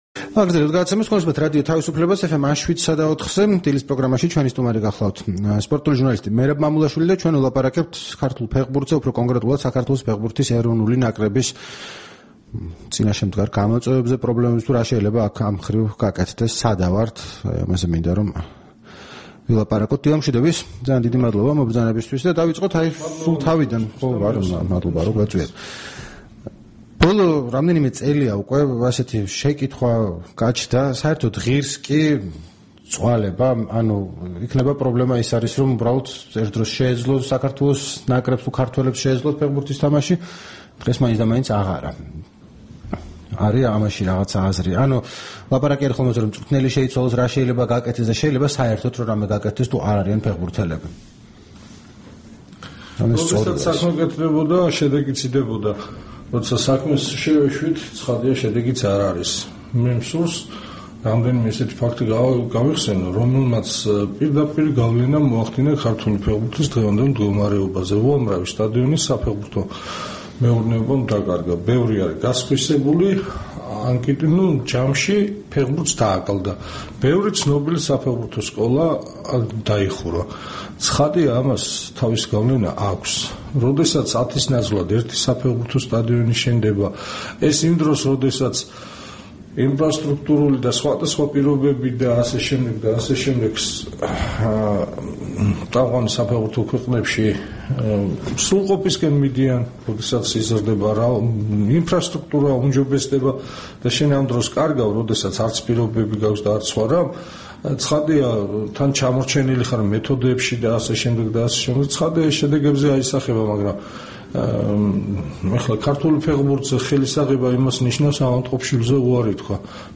რადიო თავისუფლების თბილისის სტუდიაში
საუბარი